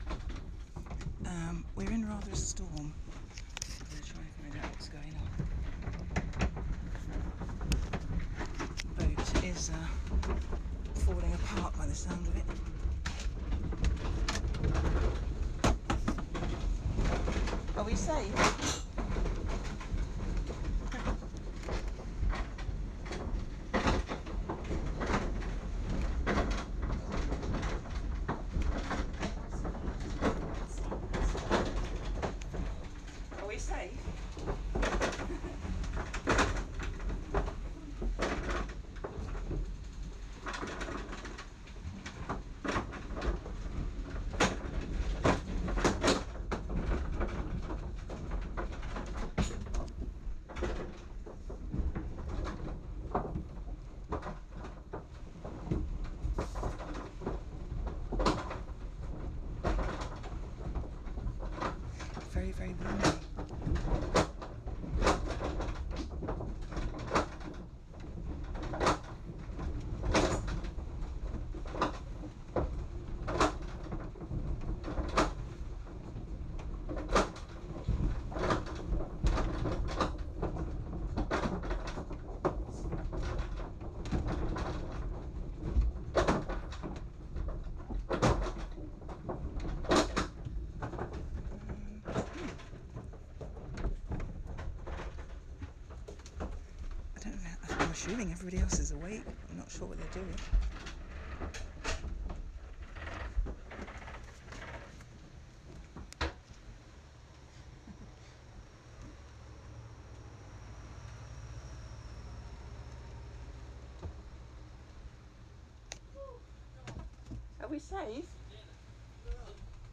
storm on a yacht in Corfu!